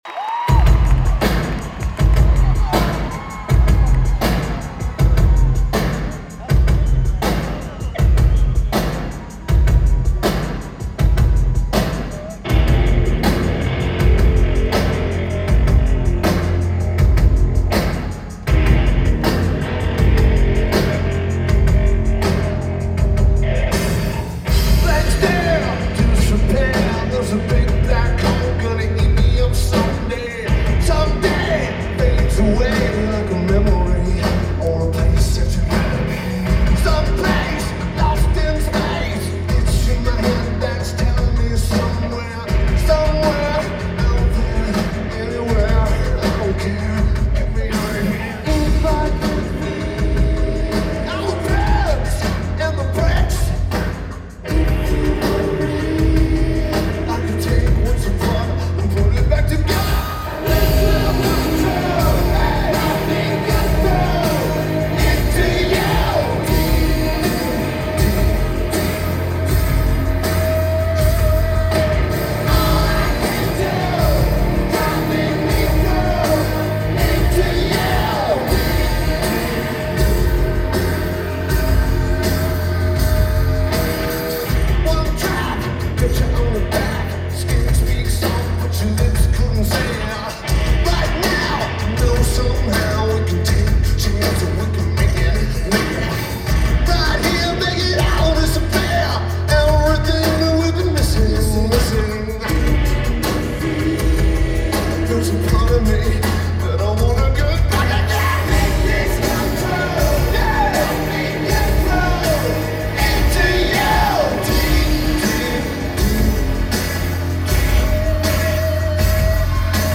San Diego, CA United States
Guitar
Drums
Lineage: Audio - AUD (Sony ECM-939LT + Sony TCD-D100)